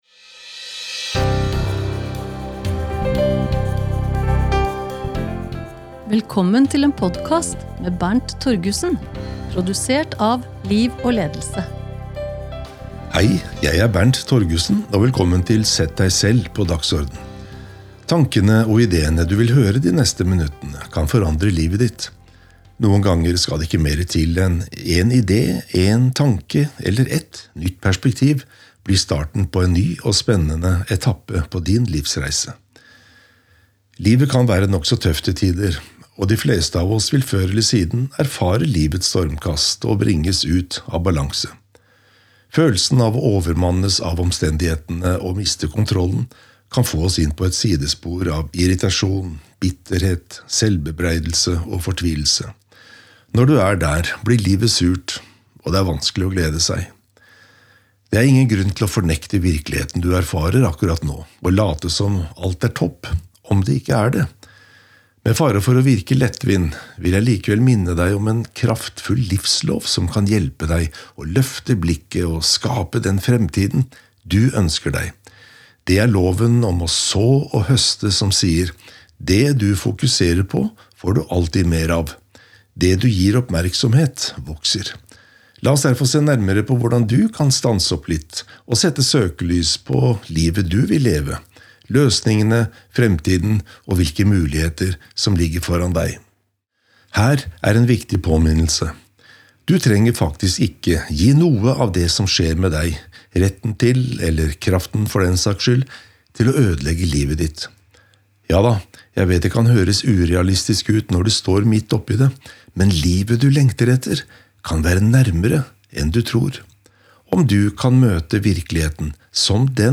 Lydbok: Sett deg selv på dagsorden